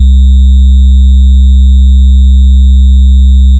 DDK1 BASSLINE 1.wav